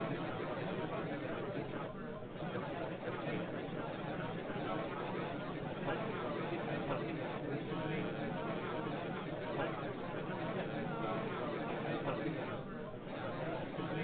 Synthesized examples twice the duration of the originals.
Babble original
BabblemontSynTex.wav